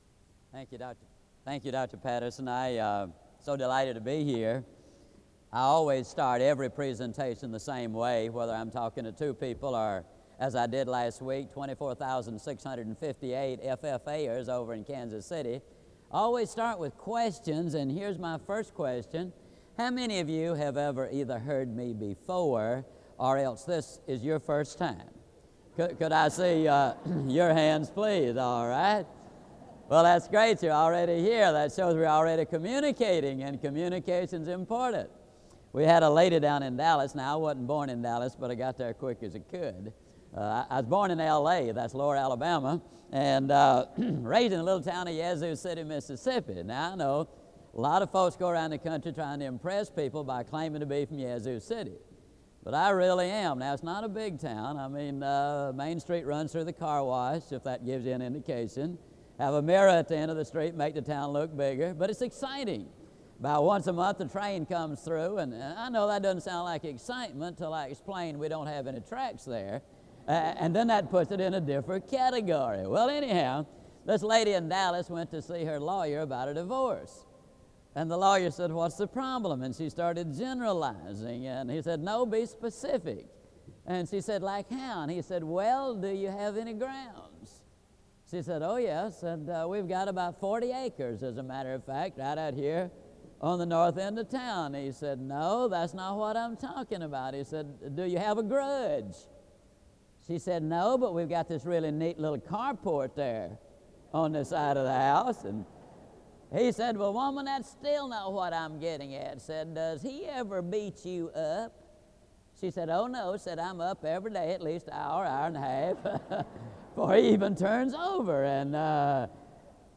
SEBTS Chapel - Zig Ziglar November 19, 1992
SEBTS Chapel and Special Event Recordings